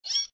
AV_rabbit_short.ogg